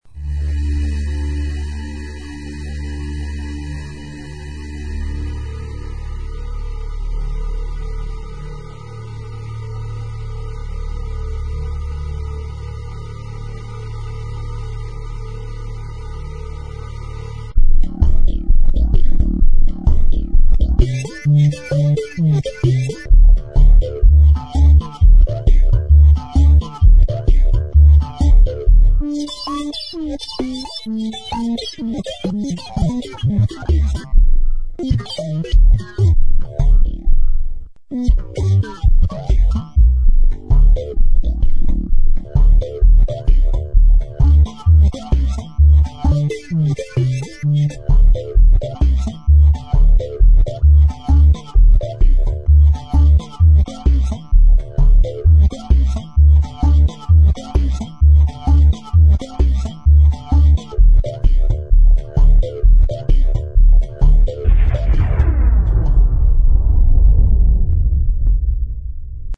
72 Stunden multimediale Kunst 2003